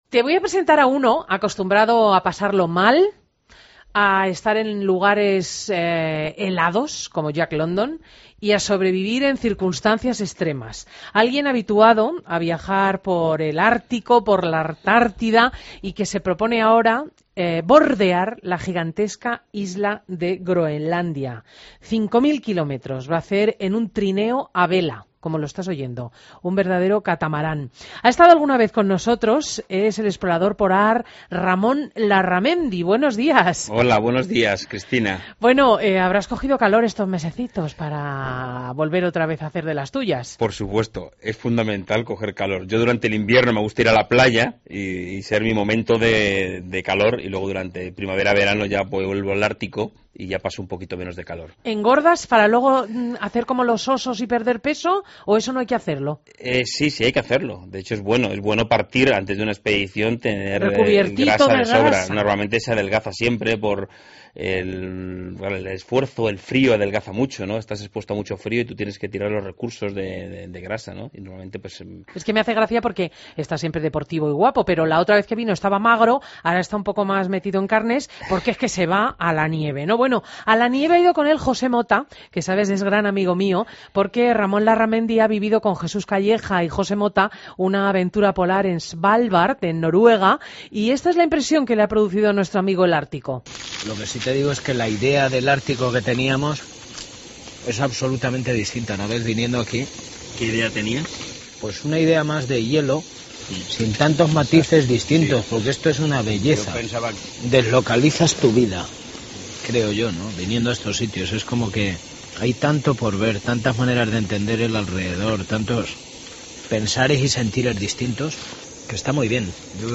AUDIO: Entrevista a Ramón Larramendi en Fin de Semana